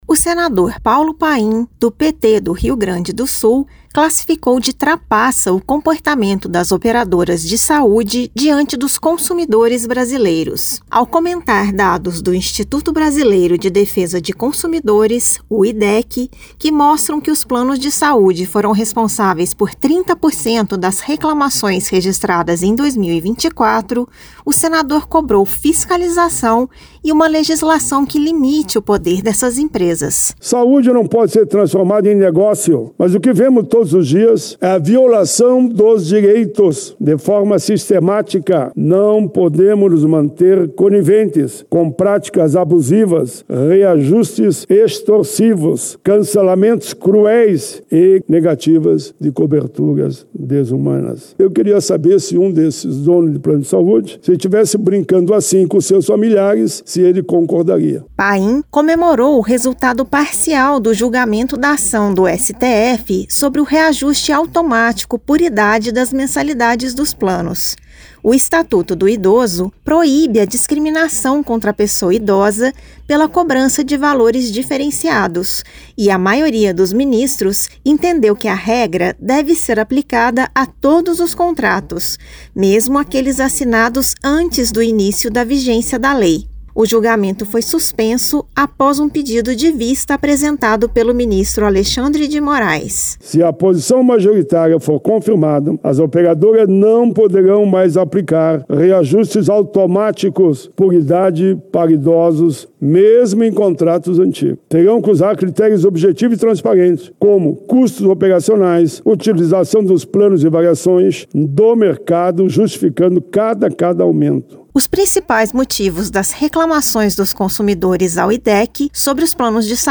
Plenário
O senador Paulo Paim (PT-RS) cobrou em pronunciamento em Plenário uma legislação forte contra a “lógica do lucro acima da vida” das operadoras de planos de saúde.
Pronunciamento